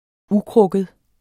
Udtale [ ˈuˌkʁɔgəð ]